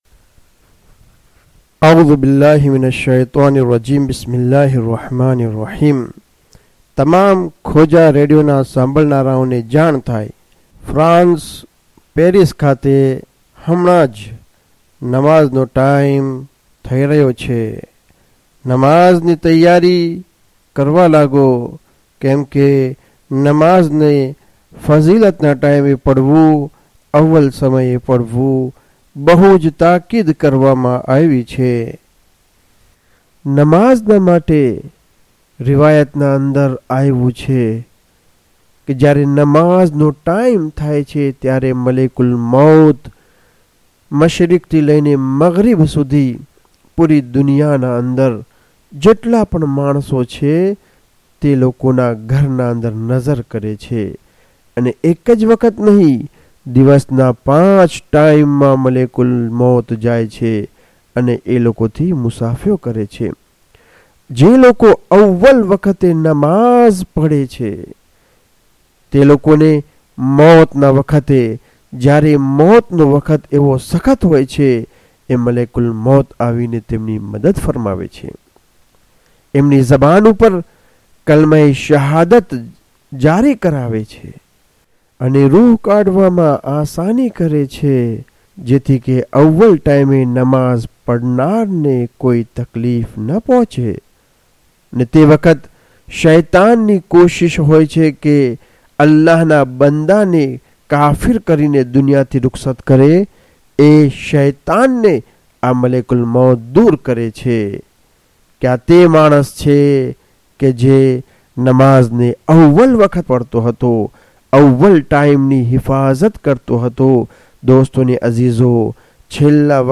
4 azan paris.mp3